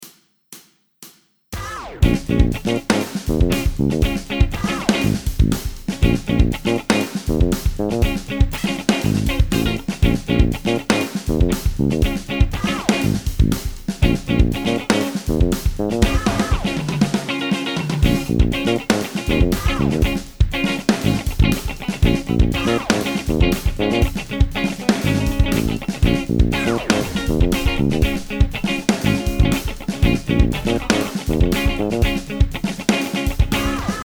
当教室で、オリジナルのギターカラオケを作成しました。
B♭ｍ７のワンコード　というコード進行になっています。
2024ibent-karaoke.mp3